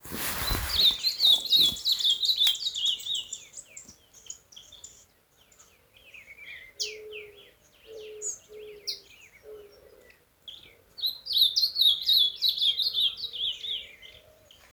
Ultramarine Grosbeak (Cyanoloxia brissonii)
Detailed location: Reserva privada Don Sebastián
Condition: Wild
Certainty: Observed, Recorded vocal